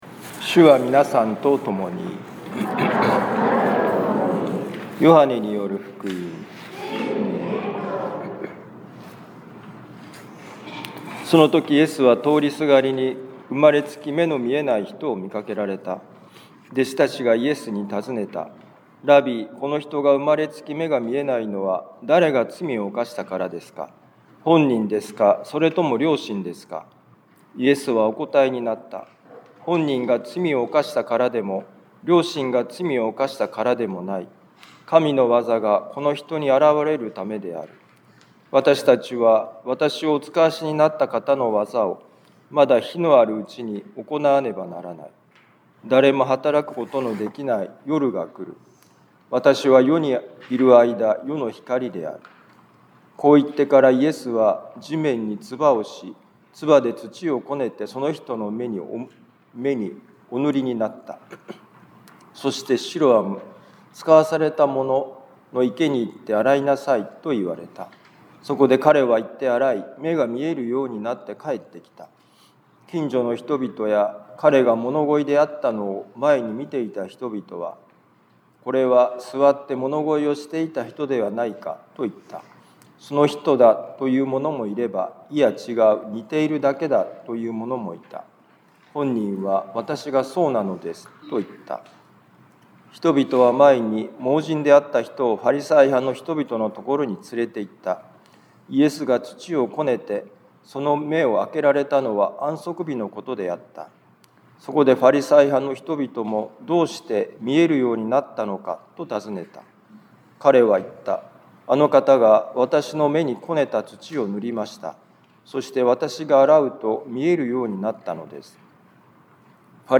ヨハネ福音書9章1-41節「神の業を見出す」2026年3月15日四旬節第4主日ミサ防府カトリック教会